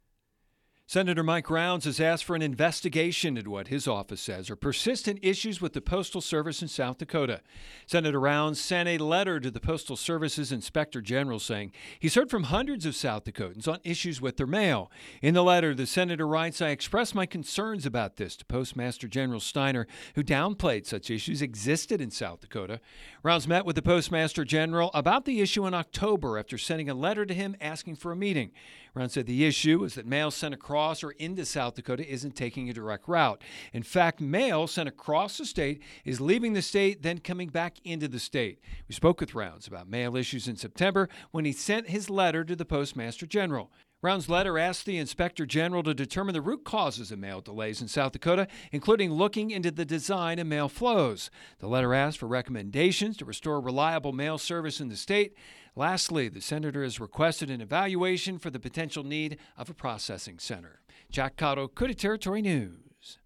wednesday-12-10-news-rounds-post-office.mp3